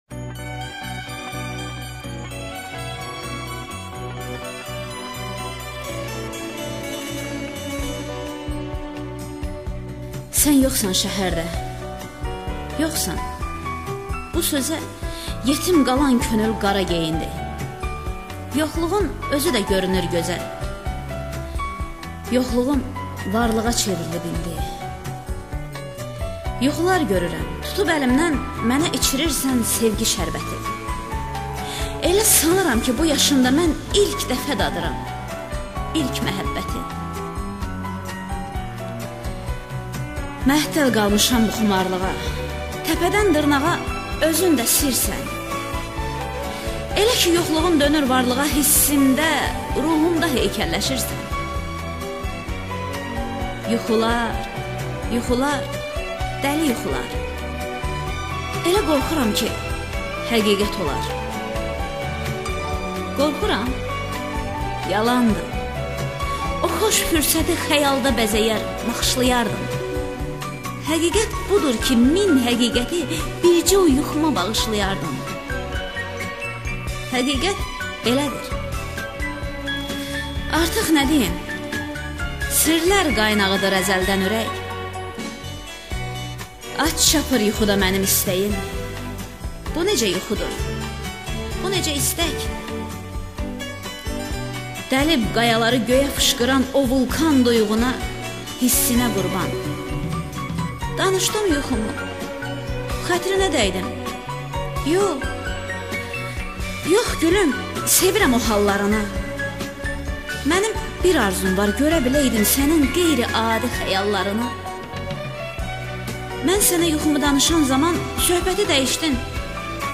ŞEİRLƏR
AKTYORLARIN İFASINDA